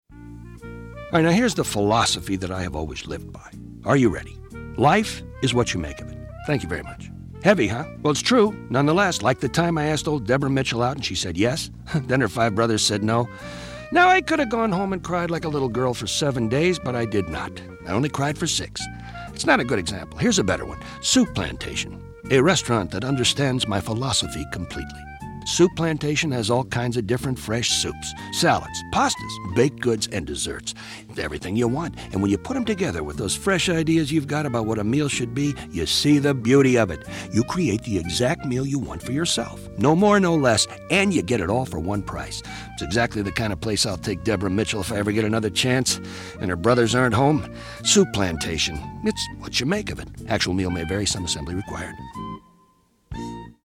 He brings humor, intelligence and an everyman quality to every spot he does.
He makes every spot he’s in sound better than it has a right to.